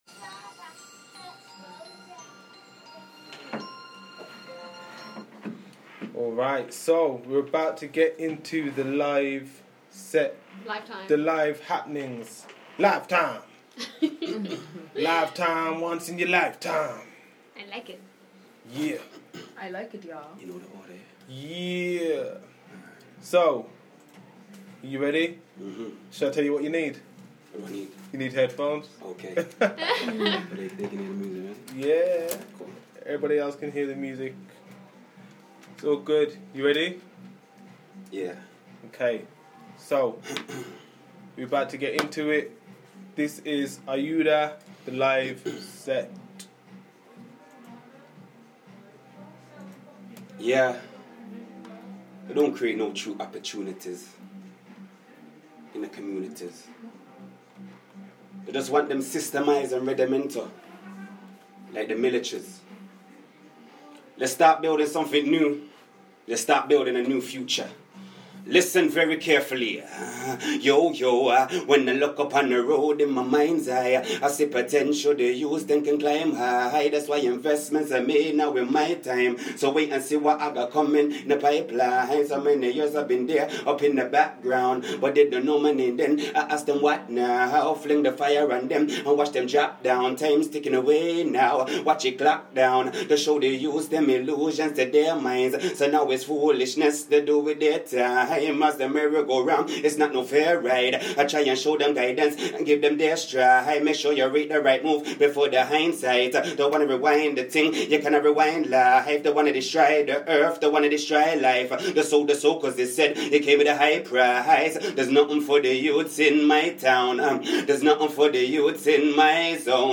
Live On The EXPOSURE Radio Show